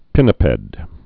(pĭnə-pĕd)